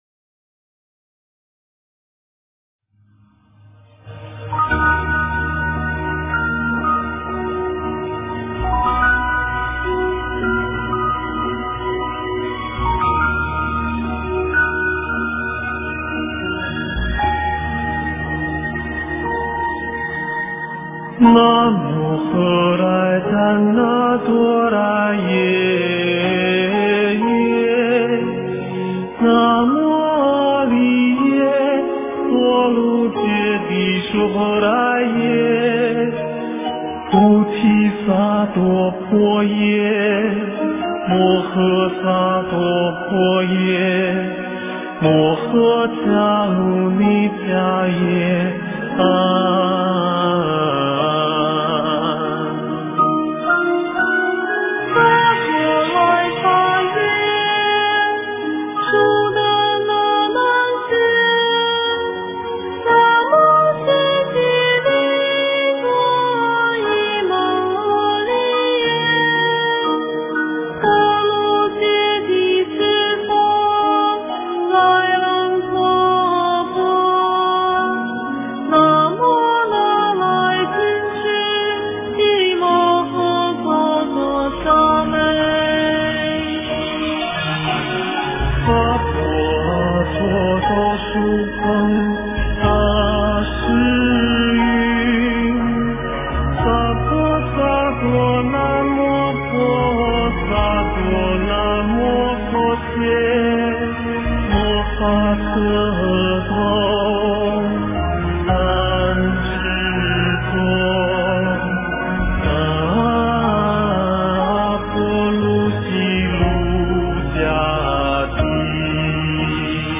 诵经
佛音 诵经 佛教音乐 返回列表 上一篇： 般若波罗密多心经 下一篇： 往生净土神咒 相关文章 天天闻佛说法--东林寺 天天闻佛说法--东林寺...